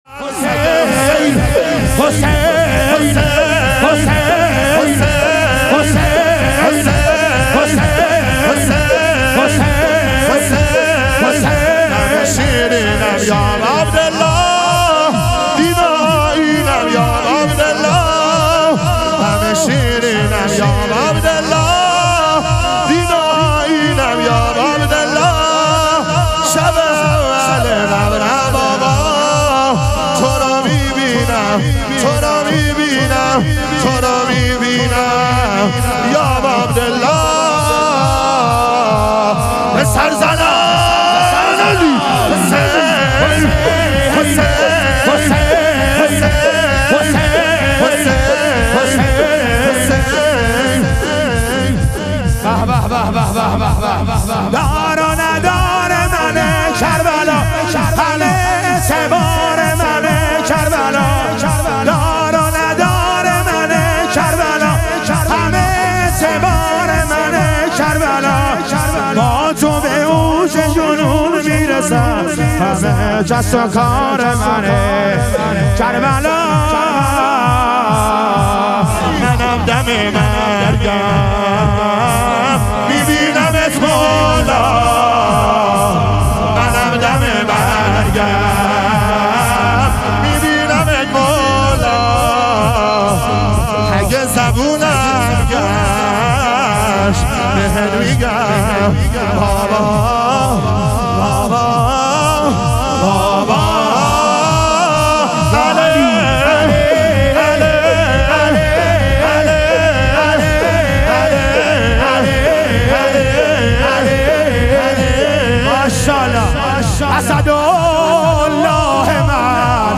شام غریبان حضرت زهرا علیها سلام - شور